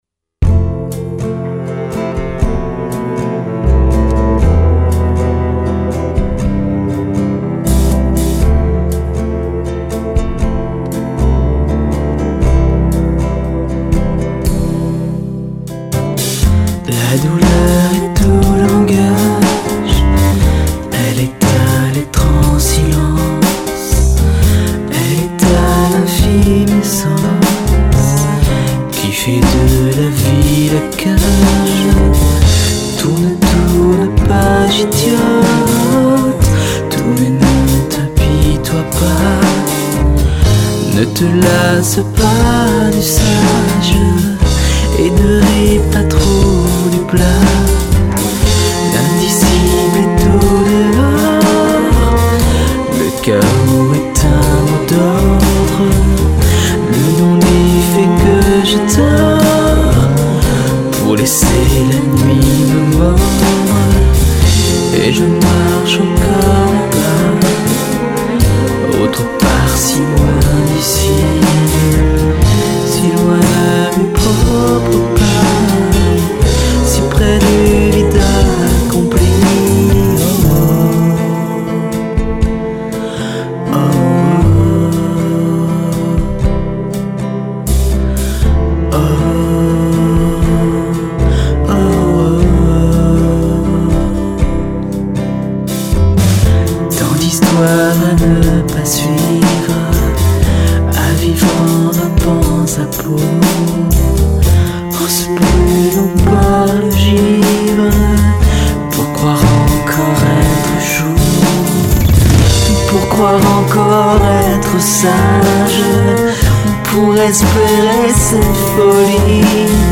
Vos Compos Chanson française
Exemples d'orchestration en cours (bon, y'a encore des trucs joués avec le coude):
Choeurs et violoncelle
nonditvioloncellemp3.mp3